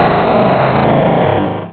Cri de Groudon dans Pokémon Rubis et Saphir.